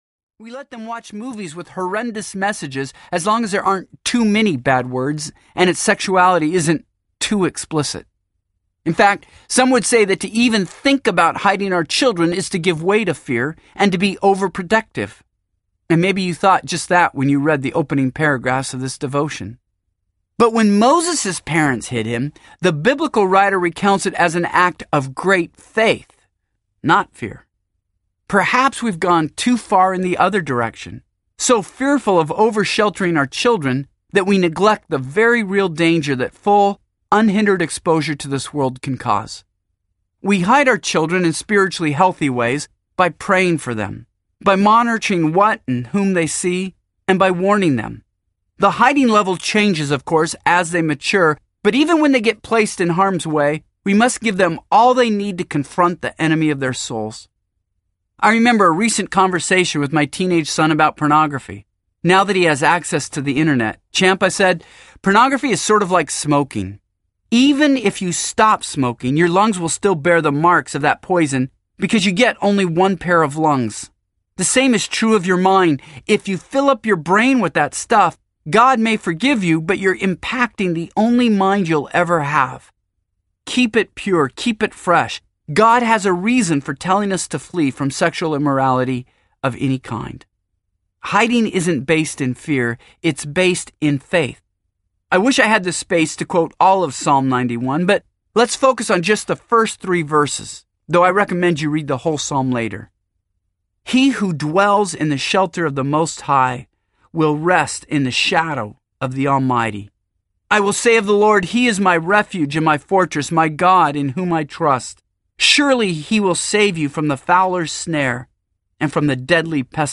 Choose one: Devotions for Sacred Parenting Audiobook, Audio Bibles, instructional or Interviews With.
Devotions for Sacred Parenting Audiobook